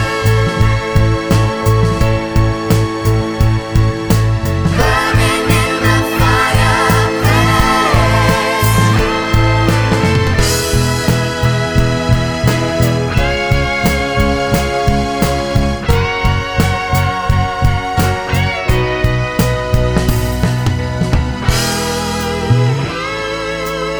no Backing Vocals Soundtracks 2:42 Buy £1.50